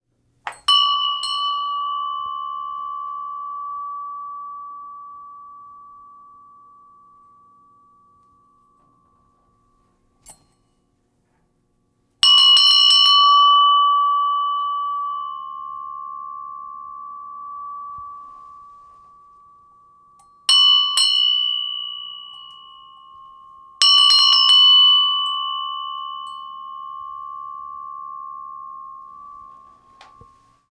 Bell ringing
bell bells chime clanging ding ring ringing sound effect free sound royalty free Sound Effects